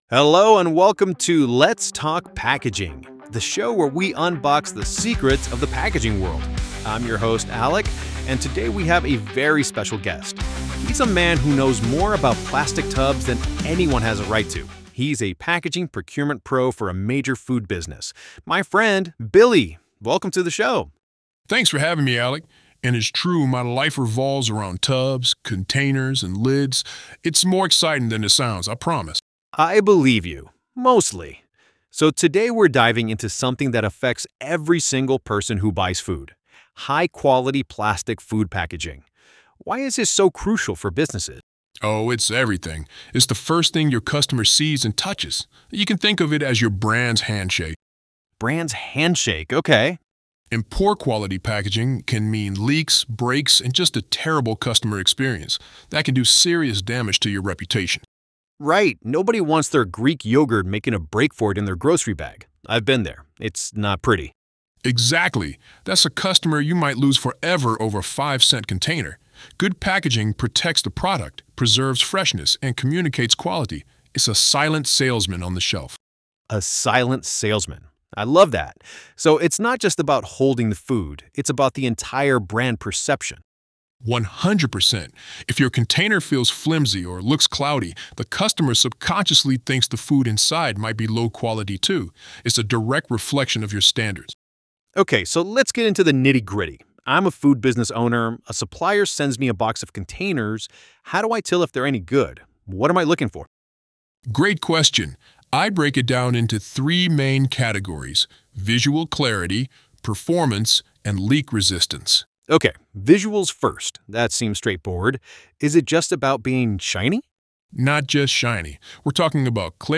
This audio uses AI-generated content and media.